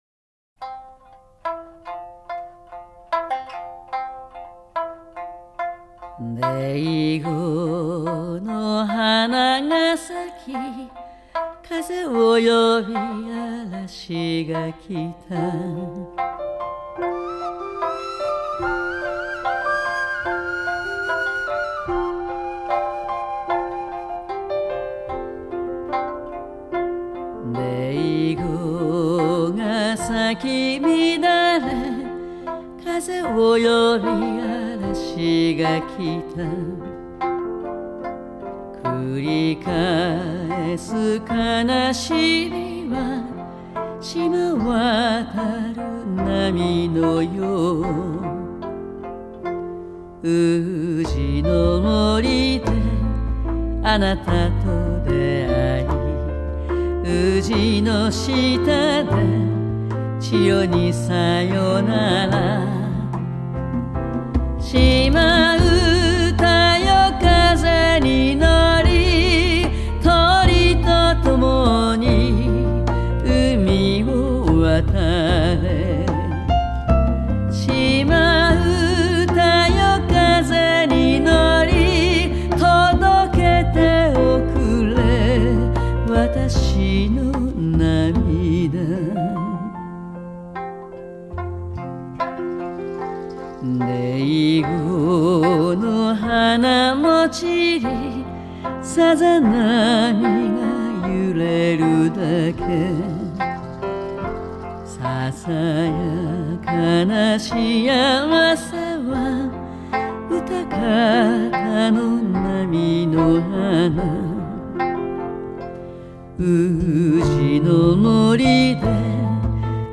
Genre: Japanese Pop